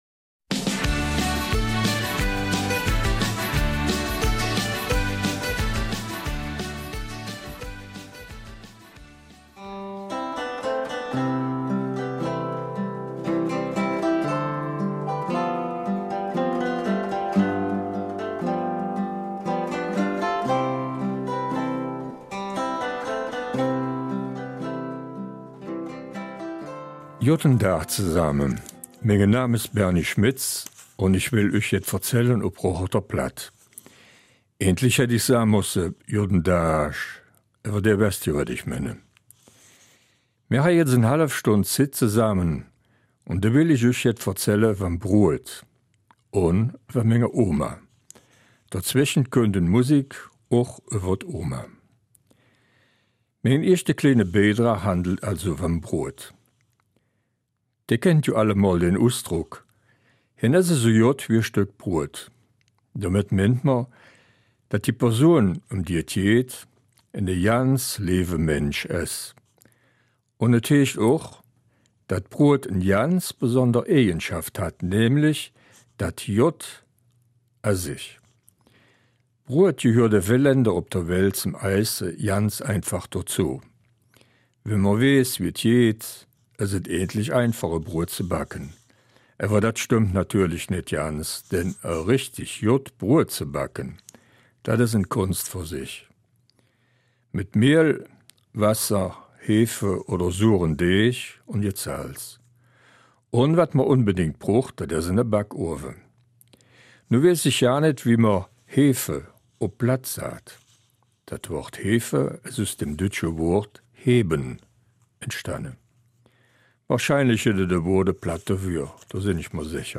Eifeler Mundart: Oma und ihr Fotoalbum